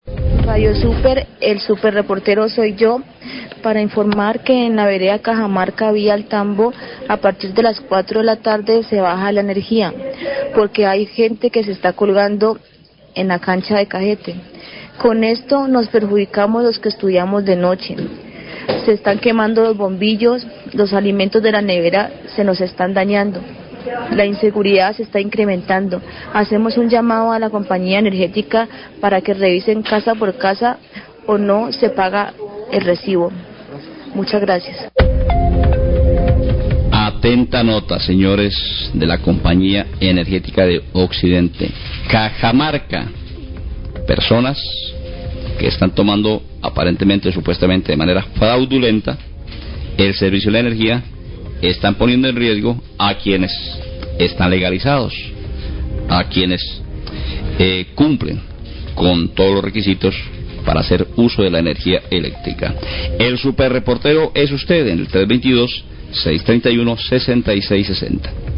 Radio
Oyente denuncia a traves de la sección el "Super reportero Soy Yo" que en la vereda Cajamarca, en la vía a El Tambo, que inescrupulosos se están realizando conexiones ilegales  alas redes de energía en horas de la tarde, lo que ha gegerado afectaciones al servicio.